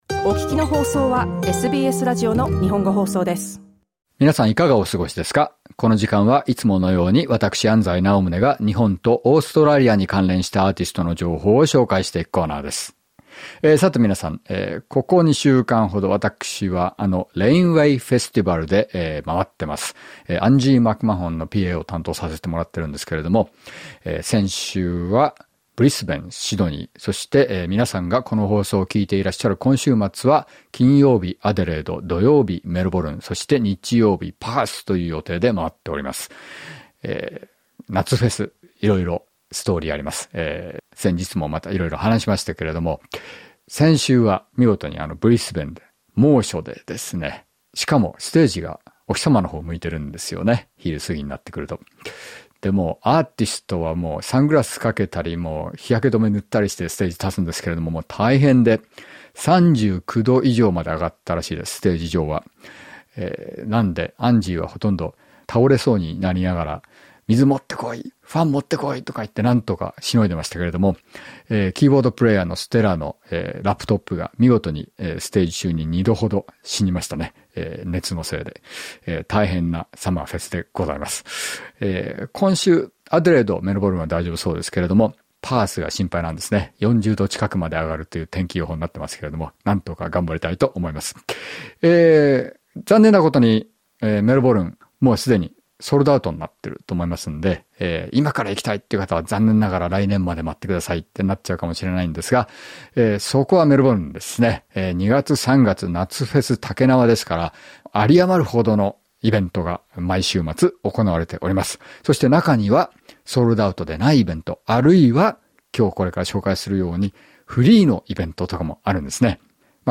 ギターやミニシンセサイザーを使ったパフォーマンスで、音楽のスタイルをエレクトロニカにも広げています。